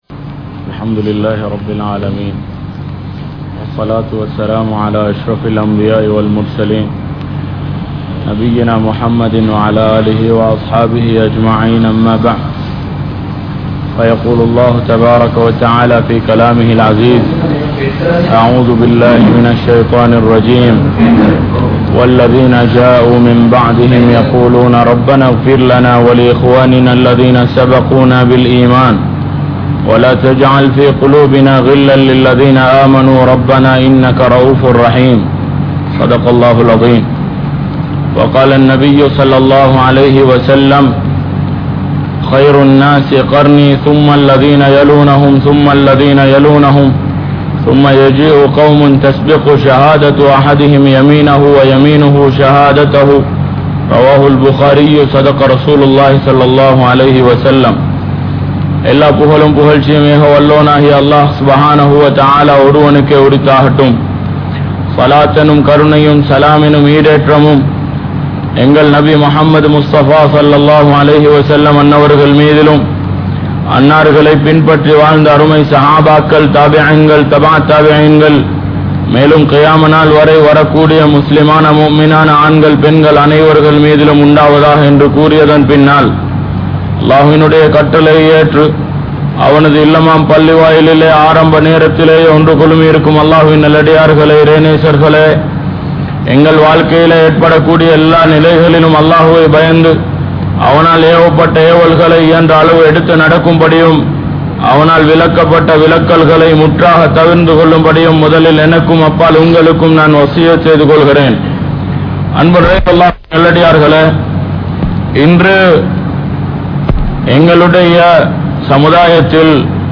Near Vali Entraal Enna? (நேர்வழி என்றால் என்ன?) | Audio Bayans | All Ceylon Muslim Youth Community | Addalaichenai
Kollupitty Jumua Masjith